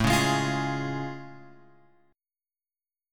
A Augmented Major 7th